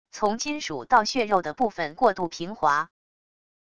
从金属到血肉的部分过渡平滑wav音频